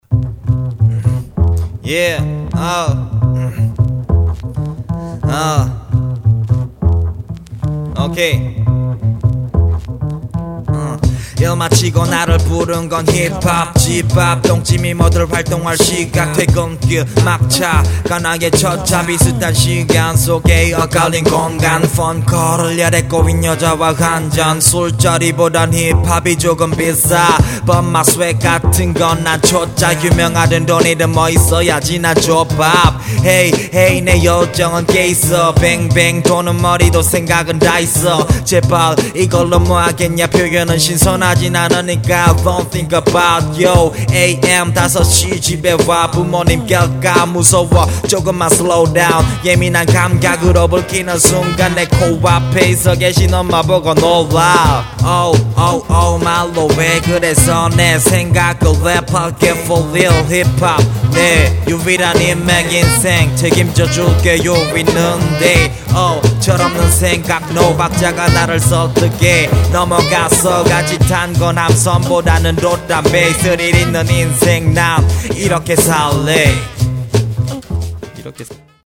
몇부분 발음만 고치면될거같아요ㅎㅎ 랩괜찬네요